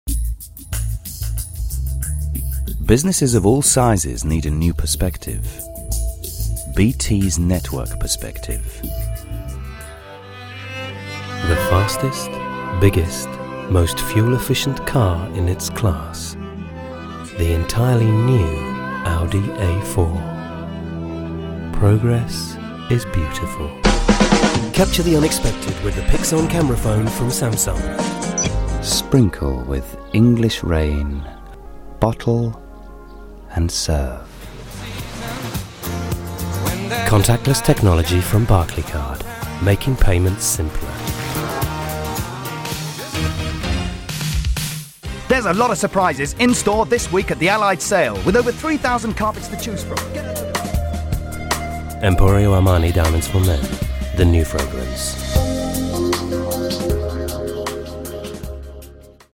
Profi-Sprecher Britisch-Englisch.
britisch
Sprechprobe: Werbung (Muttersprache):